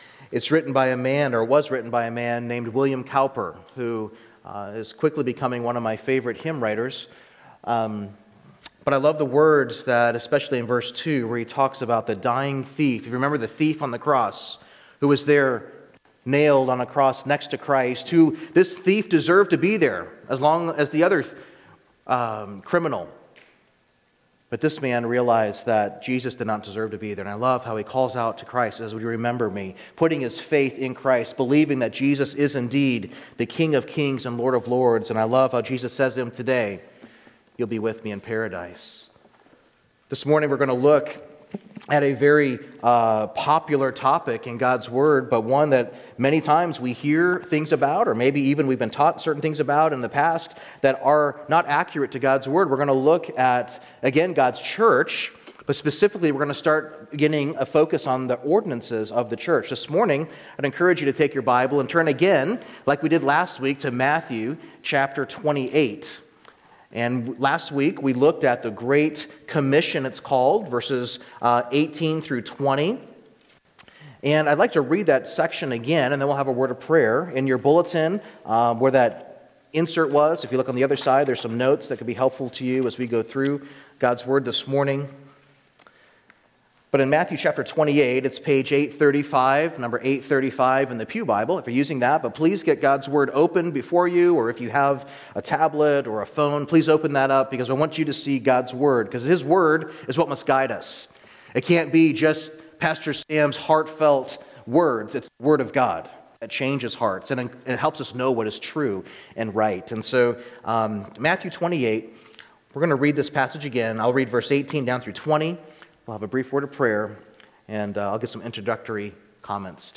Sermons | Open Door Bible Church